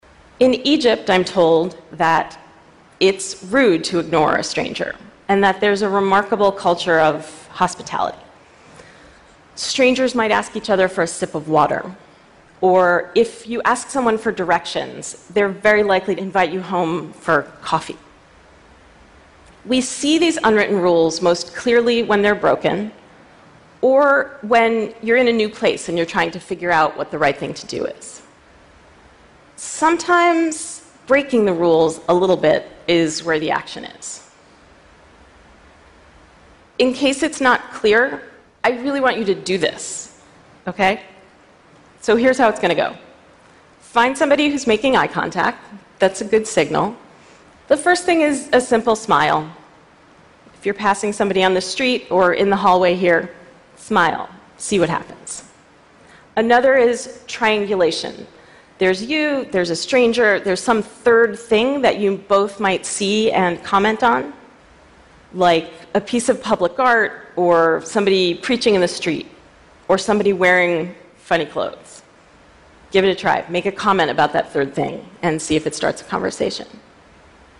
TED演讲:为什么我们需要与陌生人交流(6) 听力文件下载—在线英语听力室